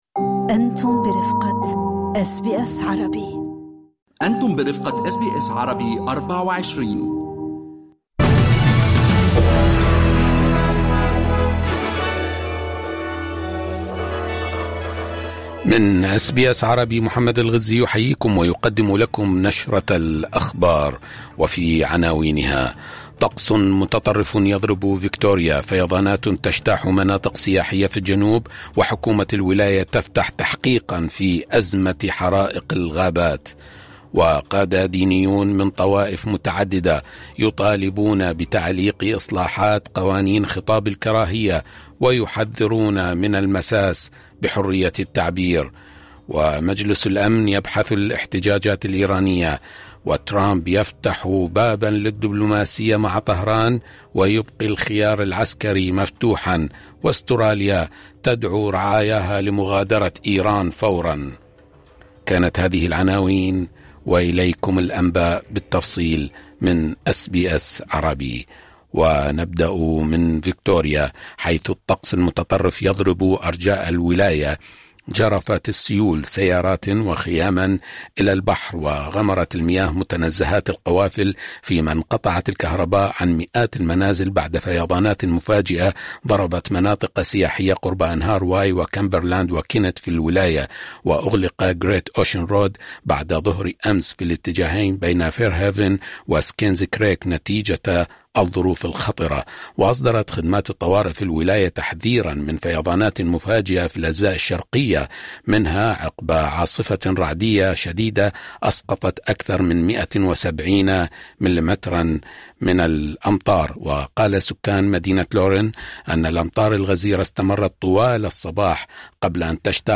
نشرة الأخبار أس بي أس عربي